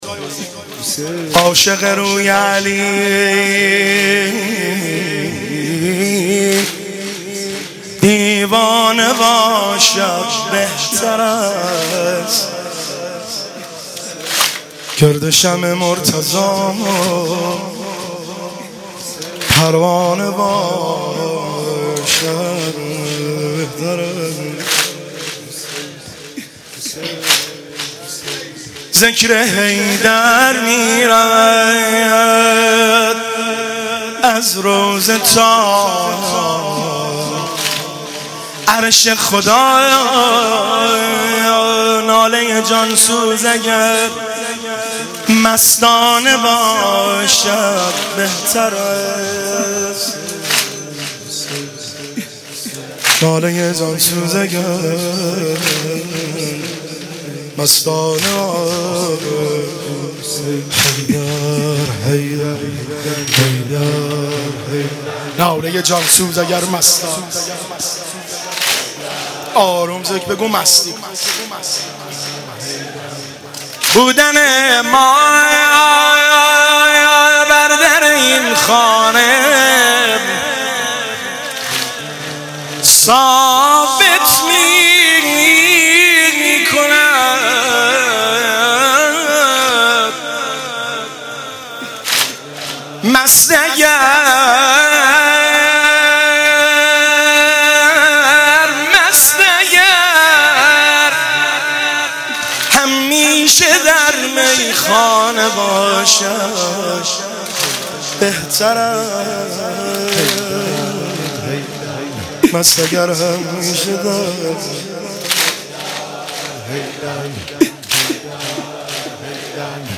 که در بین الحرمین تهران اجرا شده است
شعرخوانی